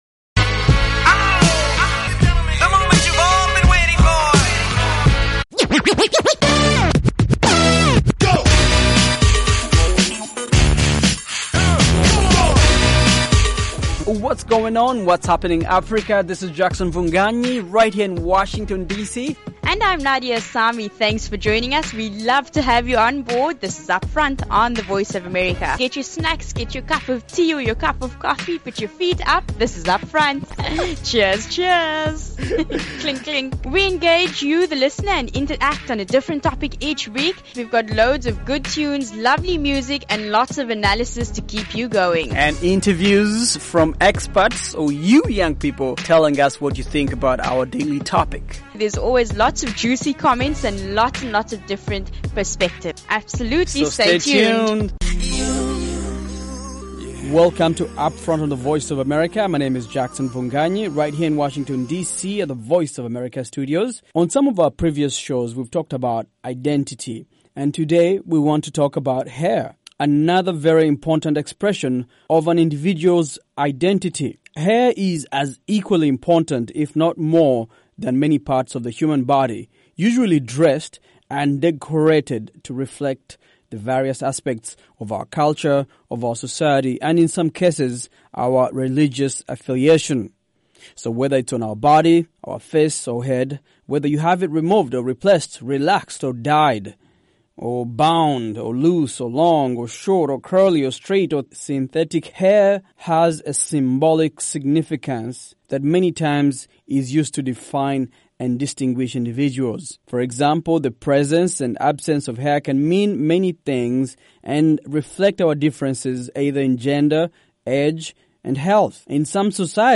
On this fresh, fast-paced show